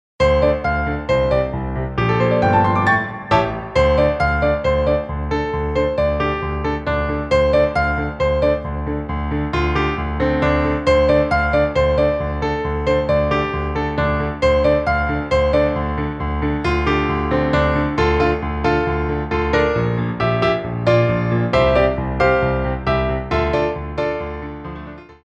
Coda
2/4 (16x8)